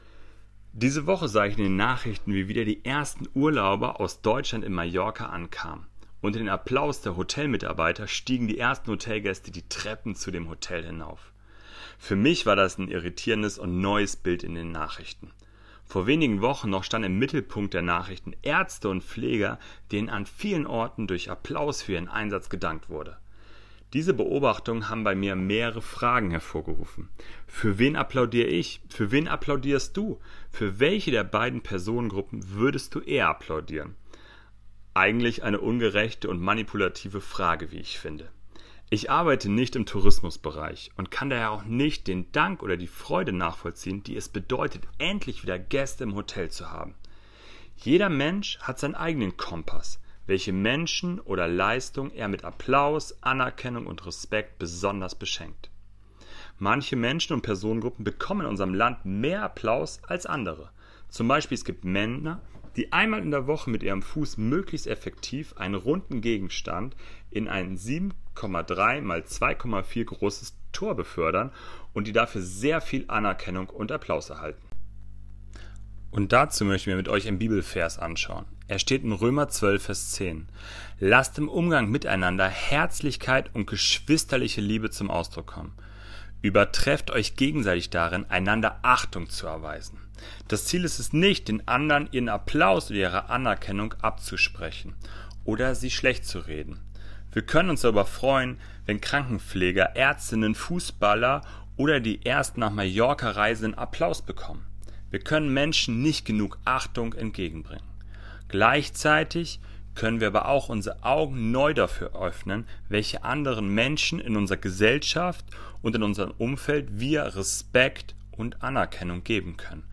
Andacht-Wer-bekommt-unseren-Applaus.mp3